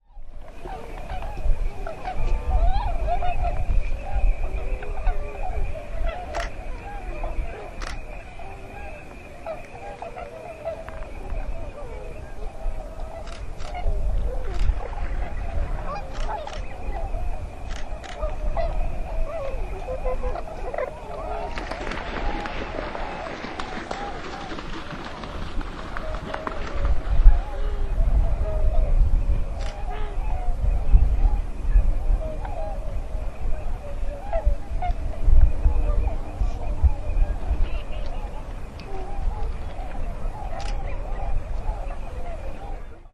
Eventually we got to one of the observation platforms that looked out over the flood plain, getting up above the grasses and able to see better.
Here we started doing some recording, because the calls of the swans (that had been gradually getting more noticeable as we drove) were now distinct, a great sound that i couldn’t decide was more reminiscent of distant coyotes or a bunch of rowdy cowboys.
Tundra swans and others at Mattamuskeet NWR
You can hear the camera in there of course, since The Girlfriend was now wielding the mic, and a little wind noise that the dead cat didn’t quite eradicate even though it did reduce it significantly. If you’re sharp-eared, you might pick out calls from other species in there – I know I could hear the American coots from time to time – but mostly you’re hearing the swans.
TundraSwans.mp3